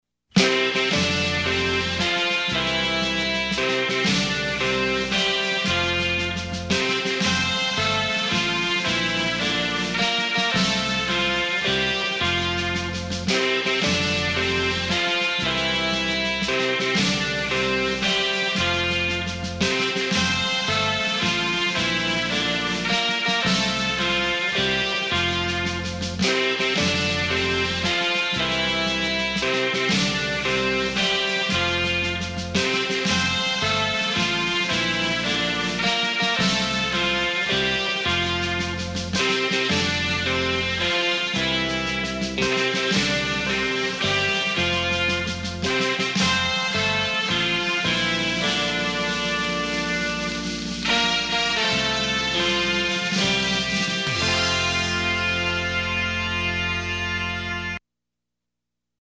Instrumental-Cumpleaños-Feliz.mp3